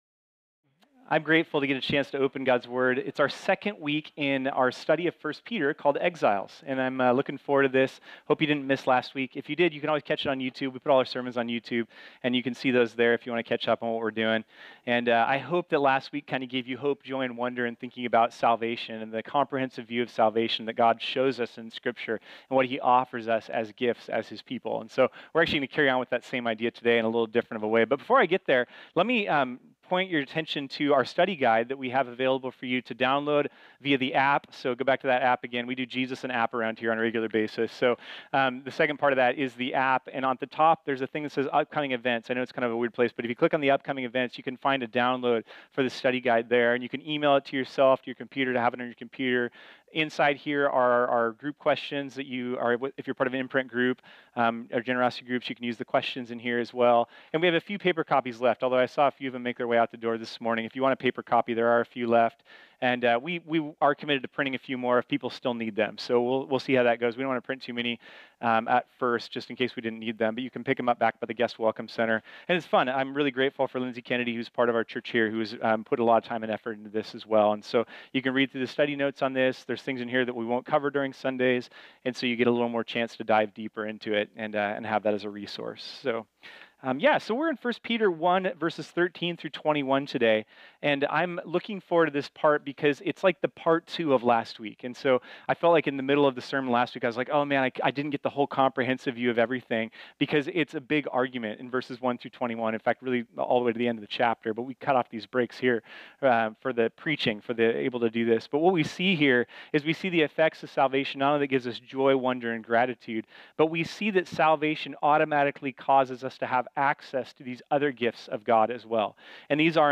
This sermon was originally preached on Sunday, February 18, 2018.